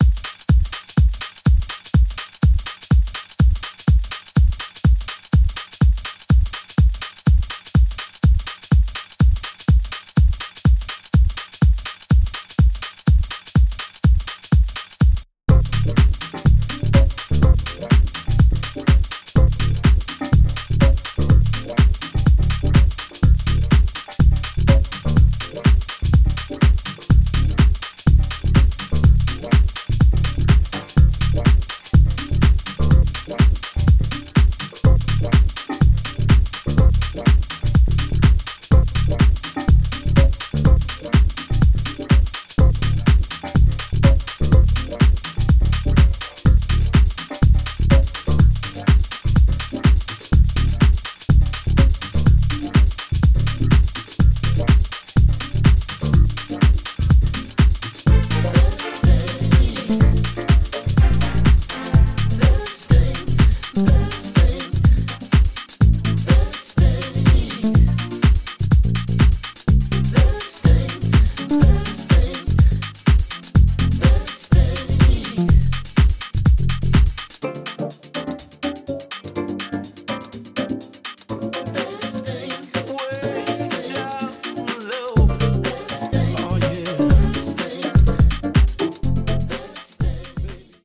シルキーで研ぎ澄まされたディープ・ハウスを展開しています！